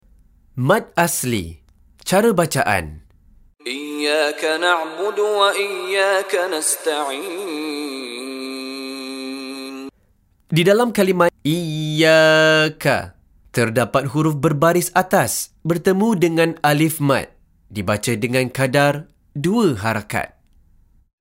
Penerangan Hukum + Contoh Bacaan dari Sheikh Mishary Rashid Al-Afasy